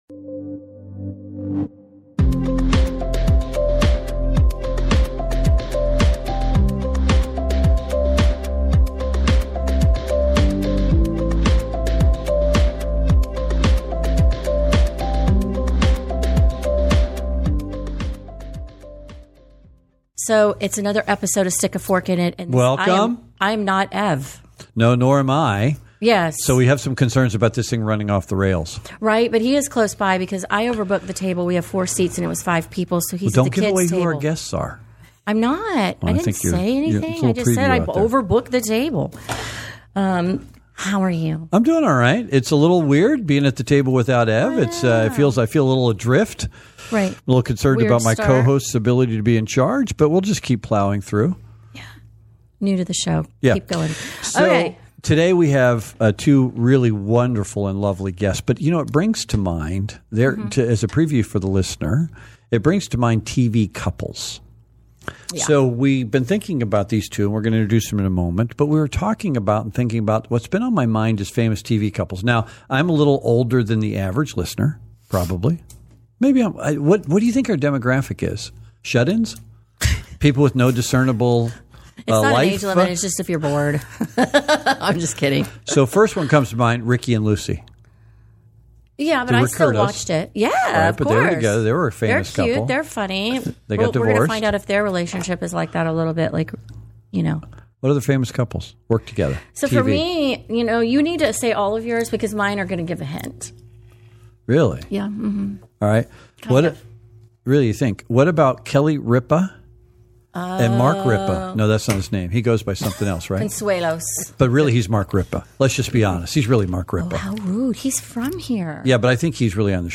In this episode of FEEDING TAMPA BAY's (FTB) podcast "Stick A Fork In It" we hear from two popular local TV hosts.